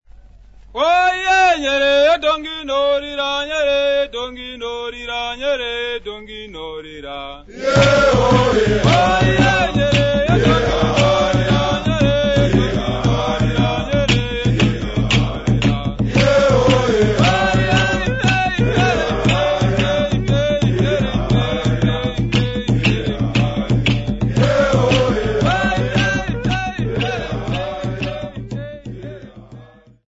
Traditional physical training tune
Indigenous folk music
Mutumba (tall drum)
Zimbabwe (Southern Rhodesia)
Traditional physical training tune by Boys of the Domboshawa School, accompanied by mutumba drum and home made dumb-bells
Original format: 15ips reel